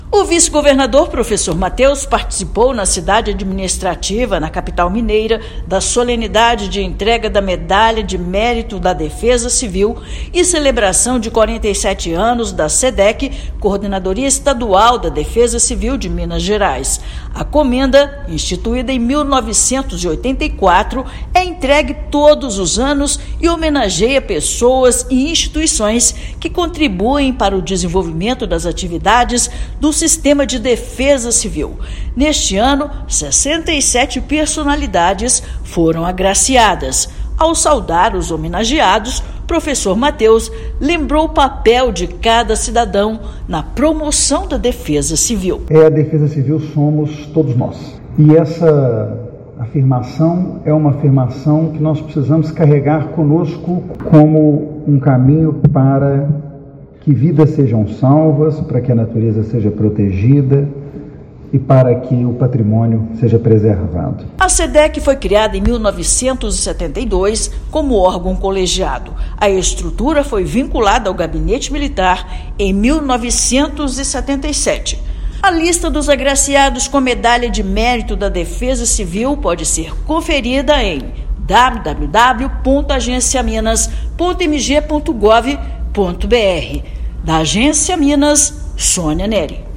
[RÁDIO] Defesa Civil de Minas Gerais celebra 47 anos com entrega de medalha que leva o nome da instituição
Durante a solenidade, que teve a participação do vice-governador, foram agraciadas 67 personalidades que contribuem com a prática de proteção e defesa civil no estado. Ouça matéria de rádio.